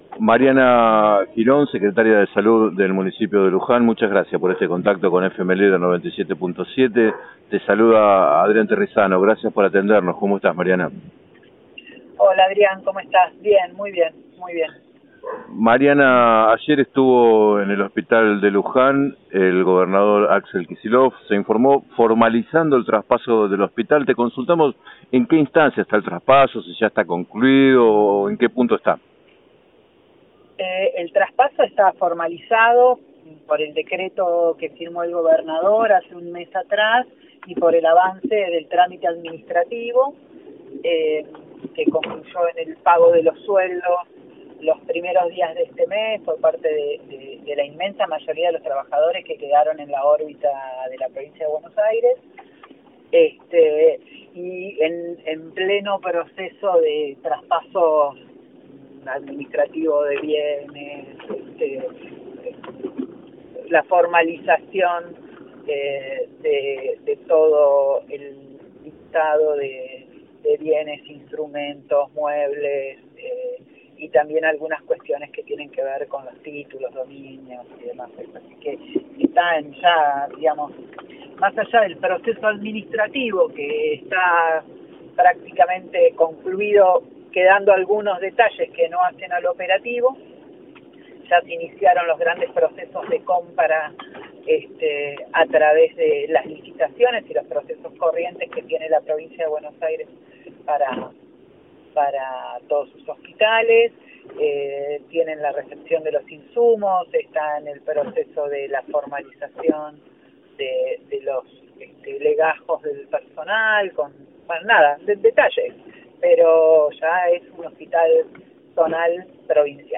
En declaraciones al programa 7 a 9 de FM Líder 97.7, Girón explicó que el Municipio trabaja de manera articulada, en una organización conjunta de la tarea “para mejorar la calidad de la salud en Luján”, con el nuevo Hospital Zonal Provincial.